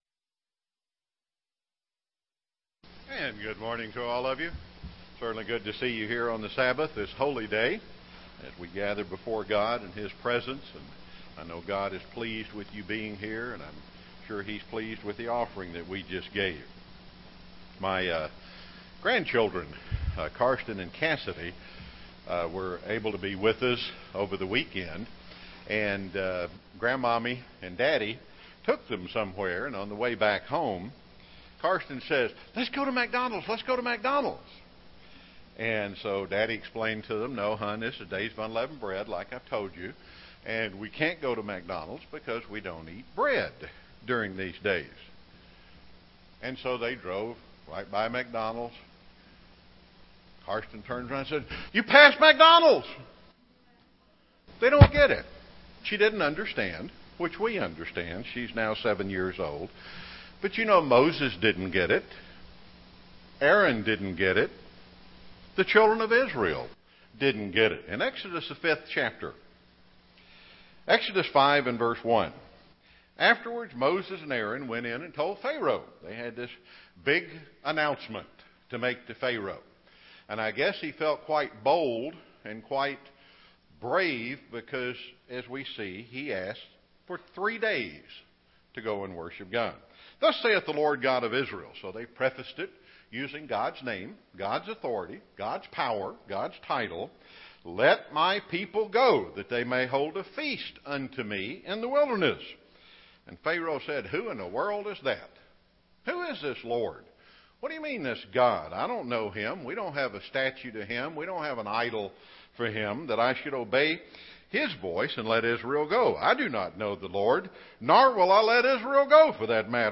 This message was given on the Last Day of Unleavened Bread.
UCG Sermon Studying the bible?